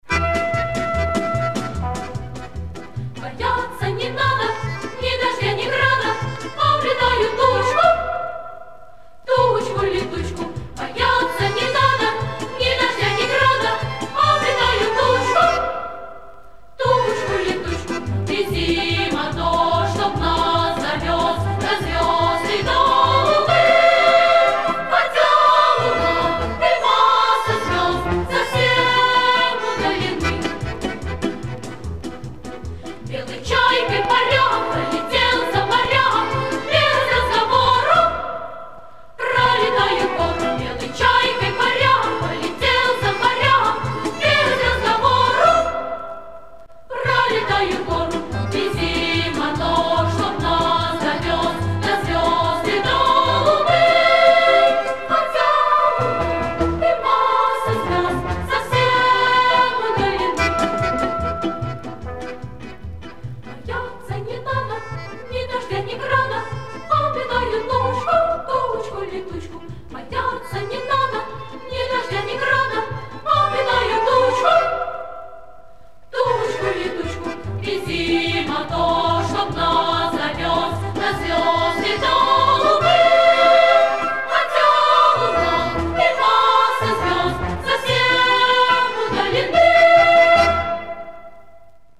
Источник собственная оцифровка